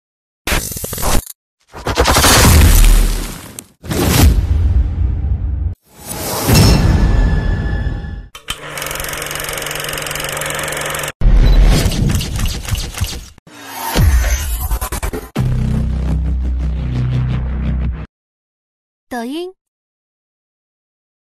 音效素材-金属故障音效
包含：重力冲击音效、快速Whoosh音效、冲击HIT、放映机拉片、炫酷的连续快门声、金属重击转场、紧张气氛音效、
【音效质量】：96kHz/24bit
音效素材-金属故障音效.mp3